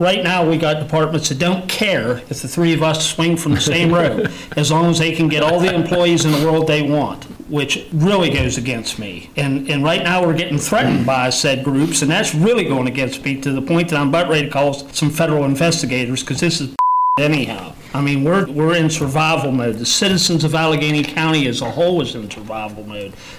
The Allegany County Commissioners met to go over options for an increasingly difficult FY26 budget season.
A decision on whether to raise property tax rates by six cents per $100,000 of value along with an income tax increase were discussed, as well as deep cuts to staff and services.  It was clear Commissioner Creade Brodie wants to protect citizens…